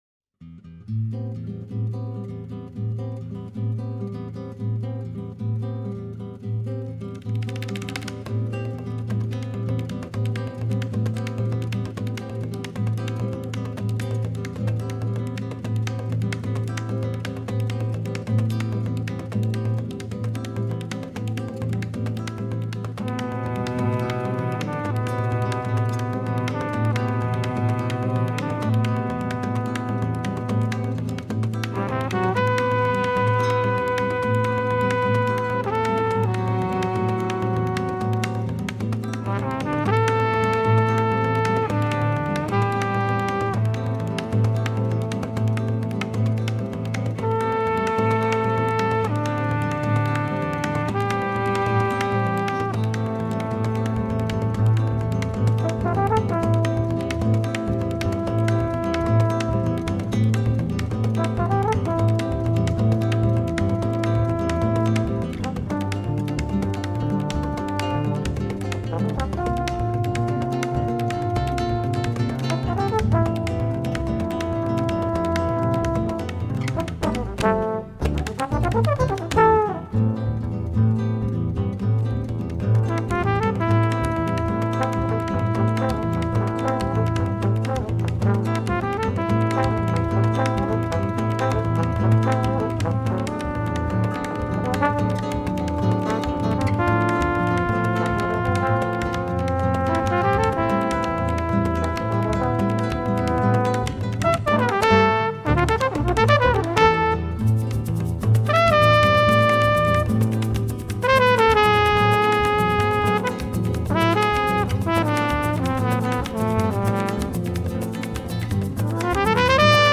Brazilian Musicians in Switzerland – Músicos Brasileiros na Suíça: Música Popular
violão